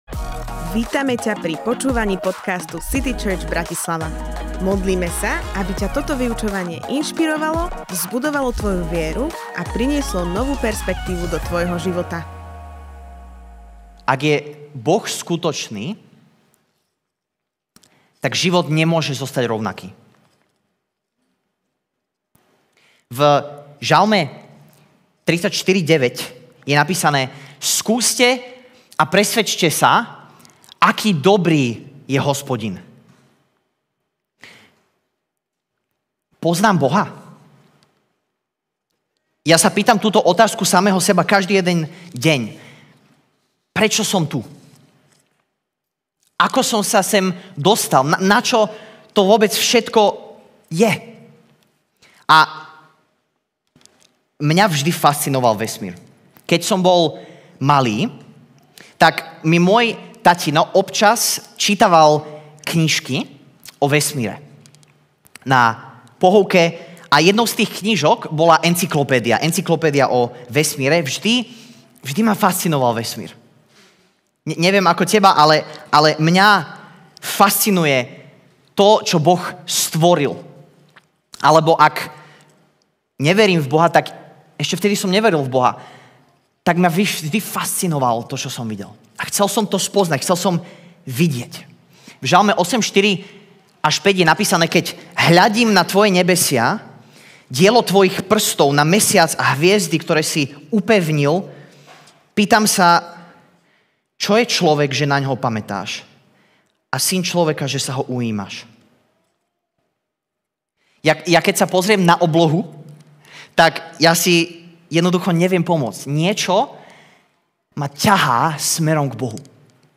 Boh je skutočný Kázeň týždňa Zo série kázní